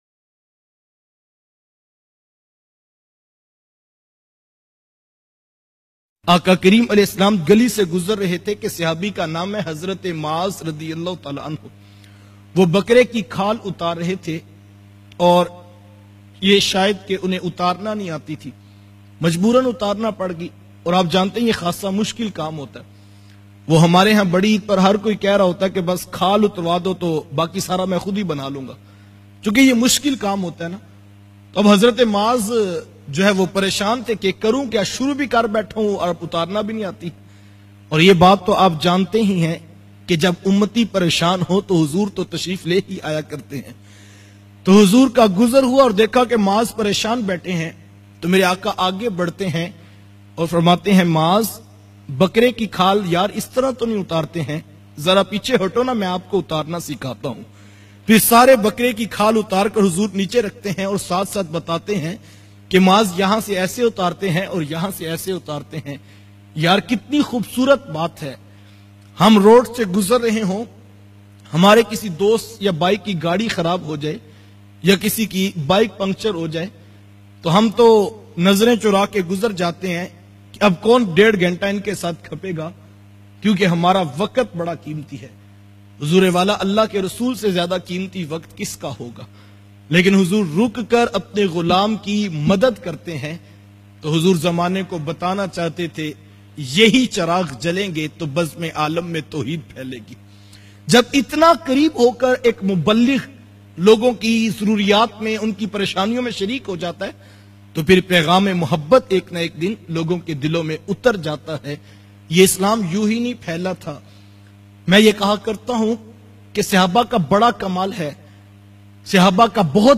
Paigam a Mohabbat a Rasool bayan mp3